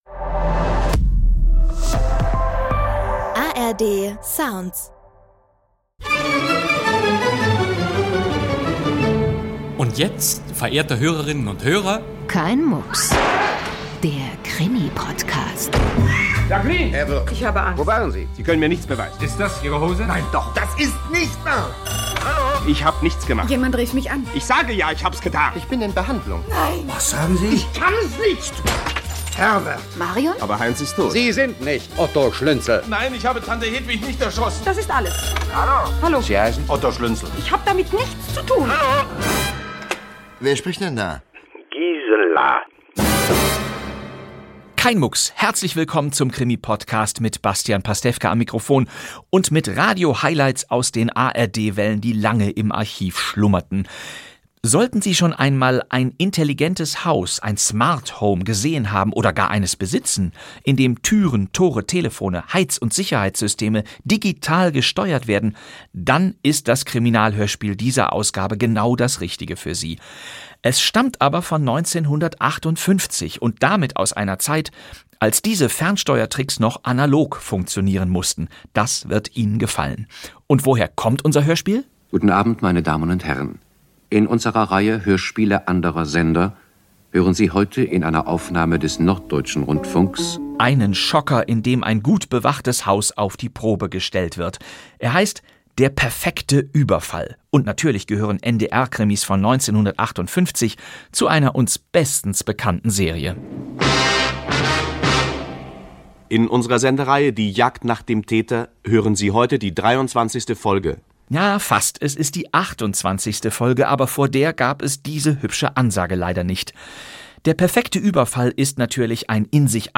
Bastian Pastewka präsentiert die 28. Folge der legendären Jagd-nach-dem-Täter-Reihe des NDR.